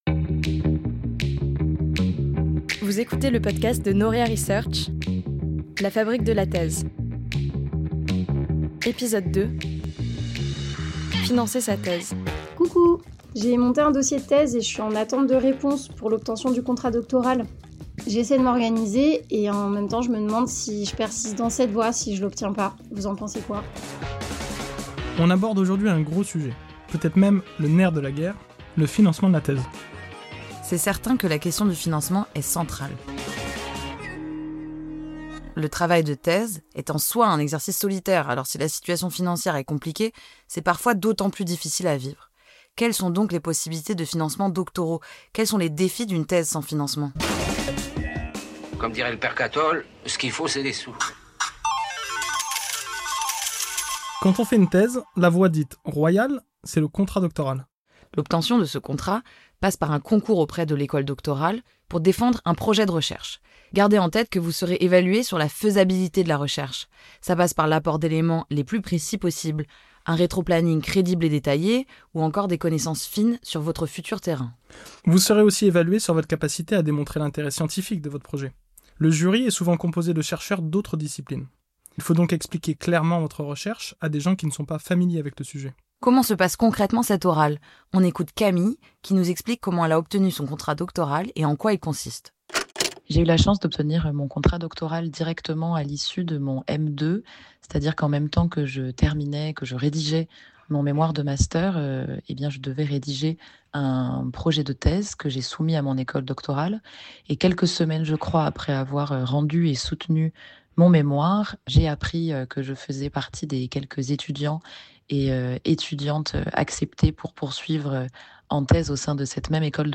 Entretien
À travers les témoignages de jeunes chercheur·ses et les conseils de l’équipe de Noria Research, ce deuxième épisode propose des retours d’expérience honnêtes pour naviguer dans le labyrinthe du financement doctoral.